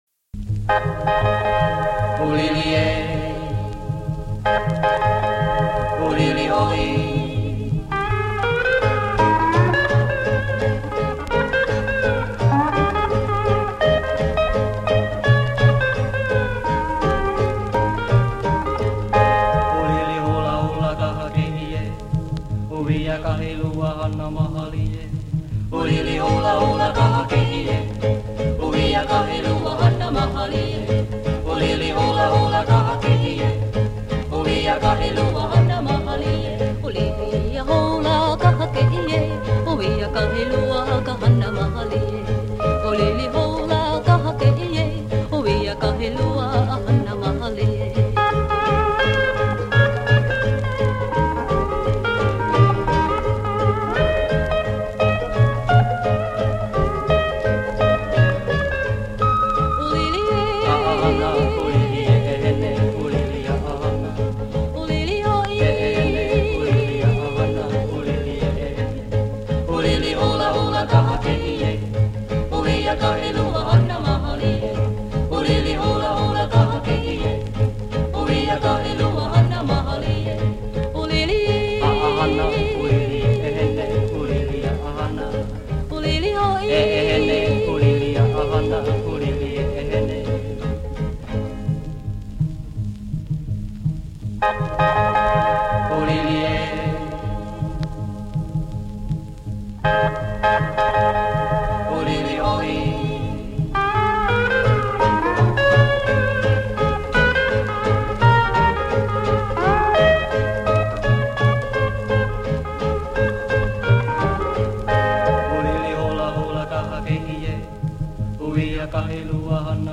has lead me to Steel Guitarist extraordinaire